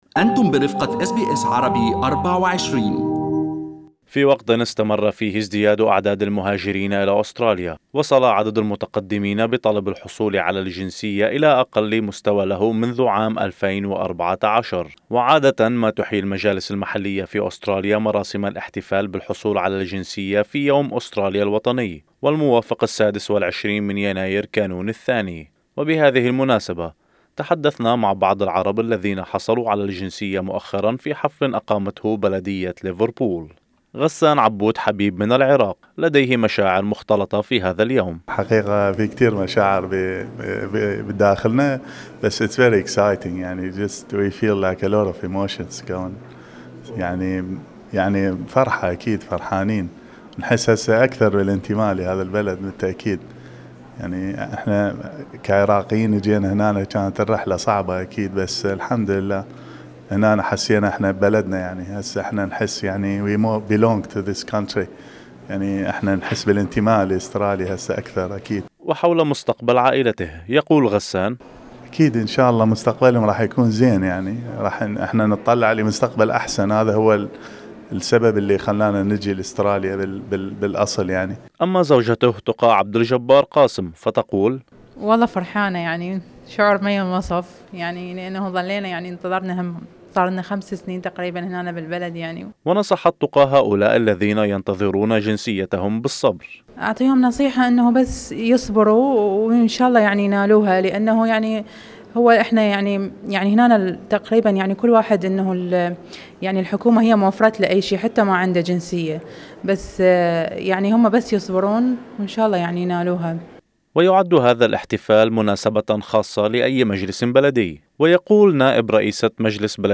وبهذه المناسبة، تحدثنا مع بعض العرب الذين حصلوا على الجنسية مؤخرا في حفل اقامته بلدية ليفربول.